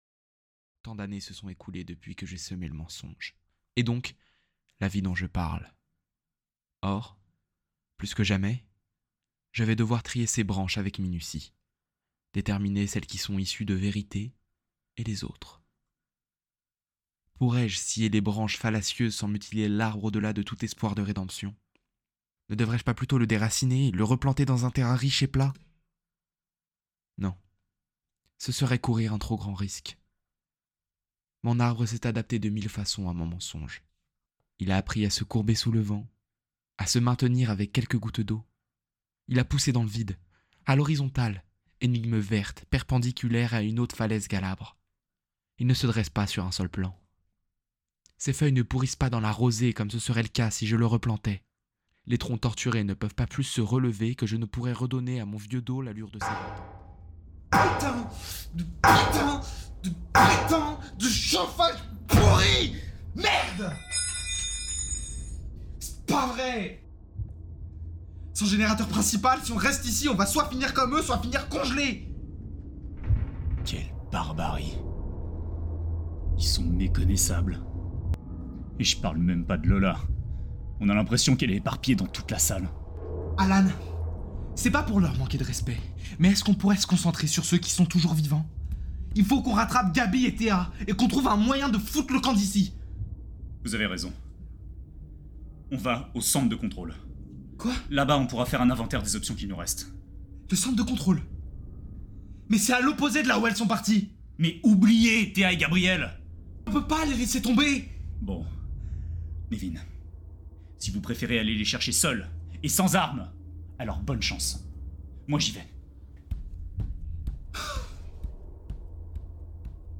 Voix off
Narrateur
20 - 30 ans - Baryton